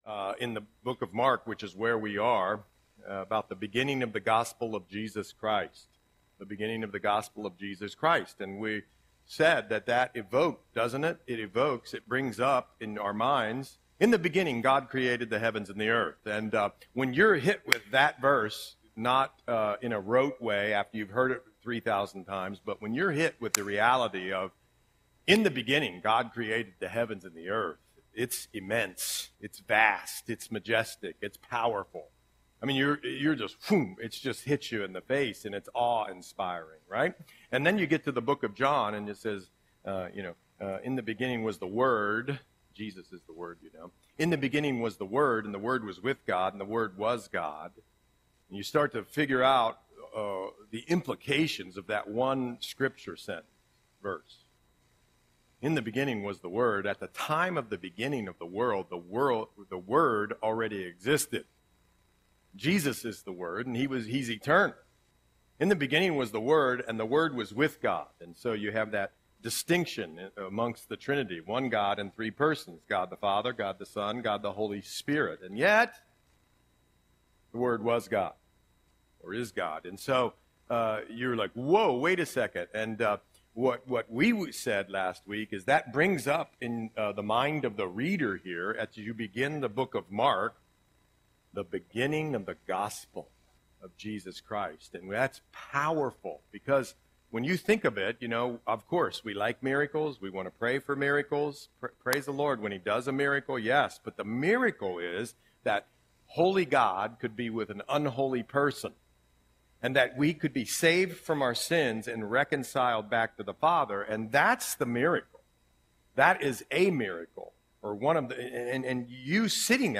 Audio Sermon - October 13, 2024